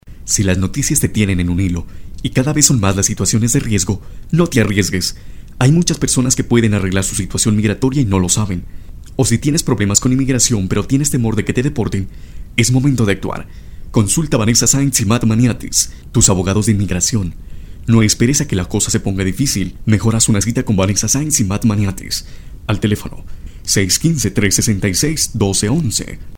kolumbianisch
Sprechprobe: Industrie (Muttersprache):